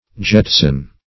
Jetsam \Jet"sam\, Jetson \Jet"son\, n. [F. jeter to throw: cf.